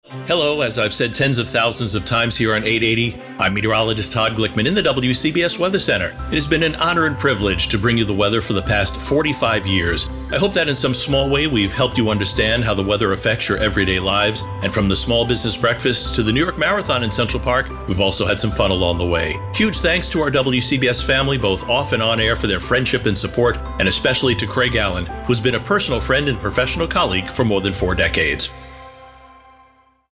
Goodbye message, 19Aug24